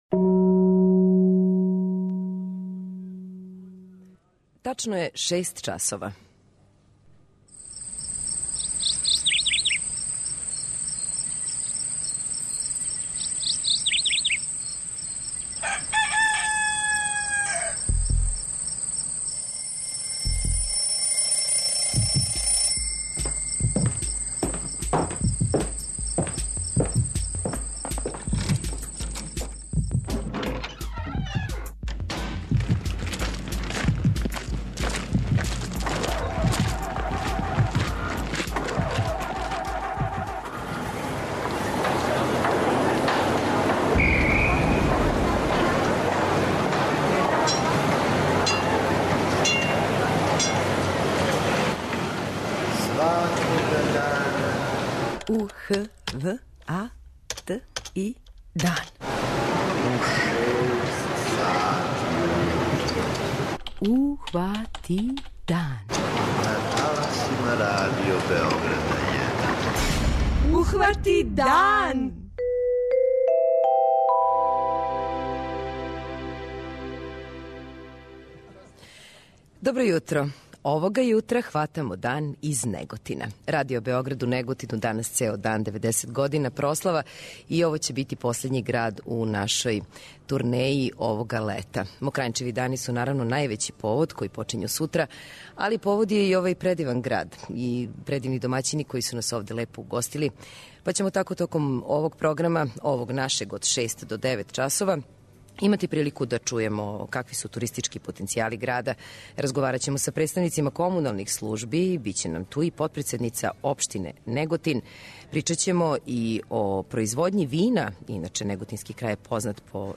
Овог јутра, хватамо дан из Неготина!